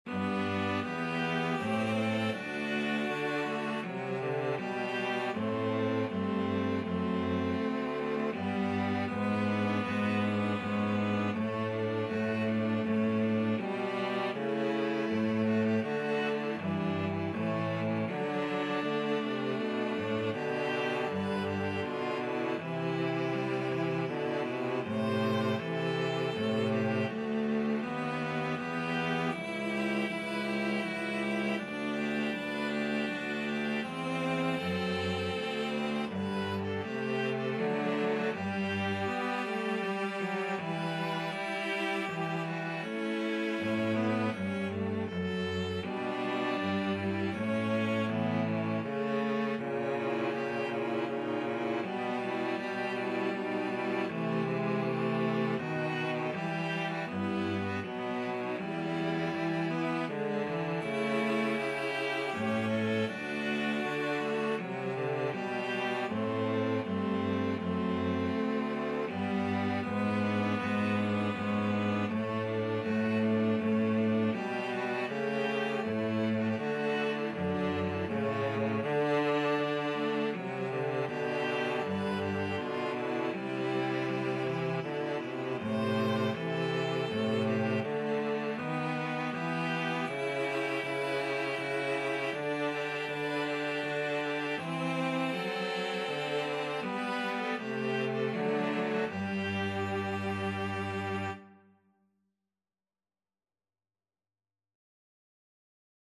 3/4 (View more 3/4 Music)
Andante
Classical (View more Classical String trio Music)